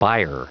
Prononciation du mot buyer en anglais (fichier audio)
Prononciation du mot : buyer